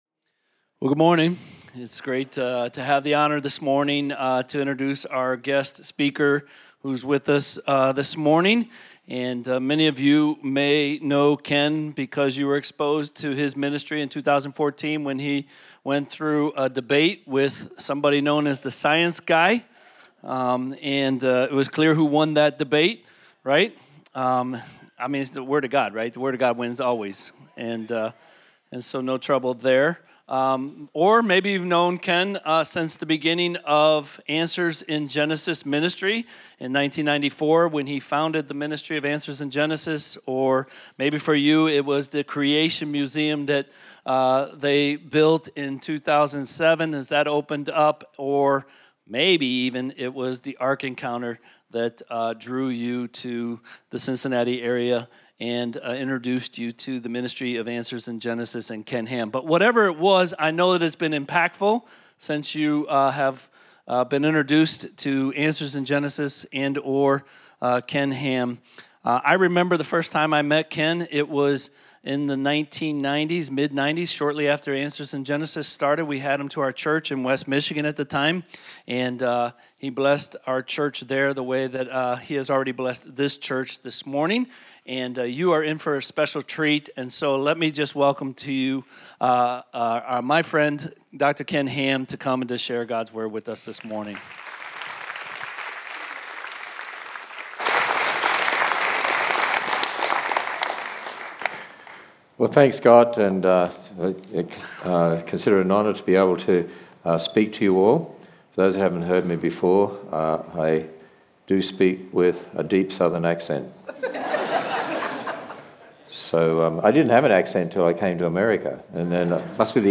Guest Speaker – Ken Ham
Home Sermons Guest Speaker – Ken Ham Guest Speaker – Ken Ham January 26, 2025 Books: Genesis Speakers: Ken Ham Download Save MP3 Scripture Reference Genesis 1-11 Related